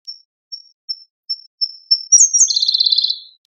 コルリのさえずり 着信音
「チンチチュルルとかチュルチチュルチ」とさえずる。